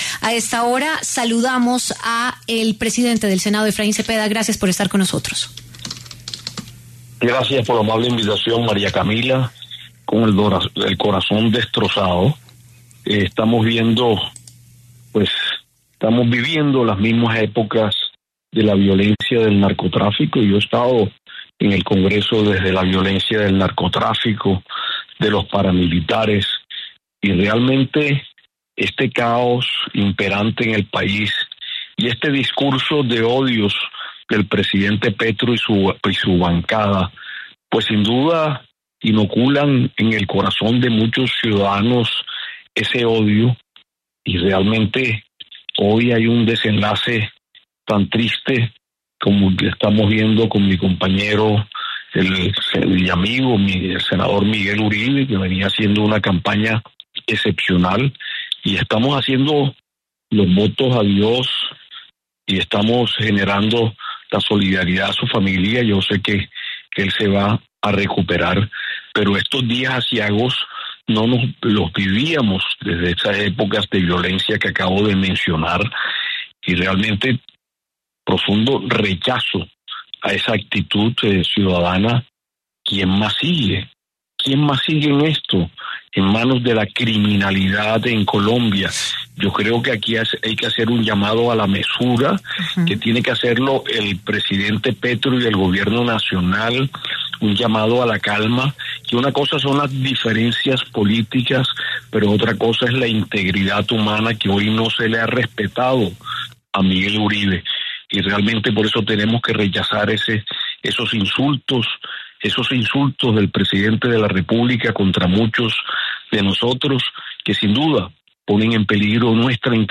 Efraín Cepeda, presidente del Senado, se refirió en W Radio al atentado contra el precandidato presidencial Miguel Uribe.